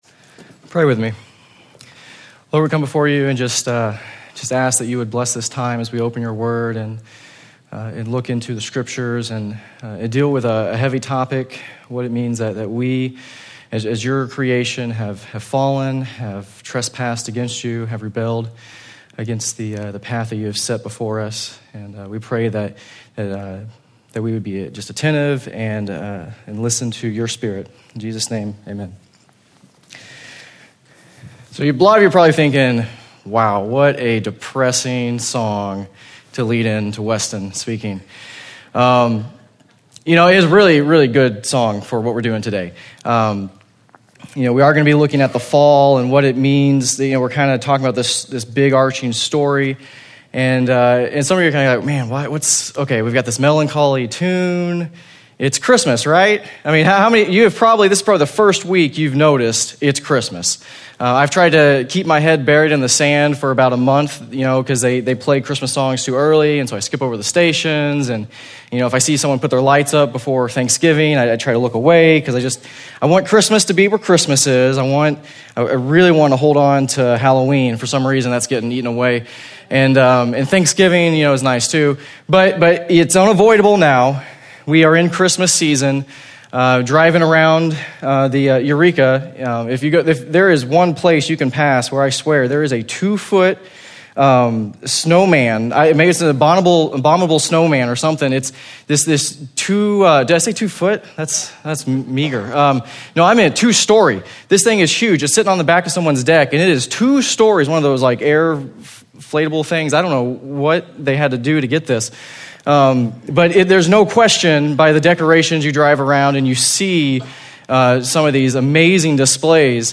The December 2011 Sermon Audio archive of Genesis Church.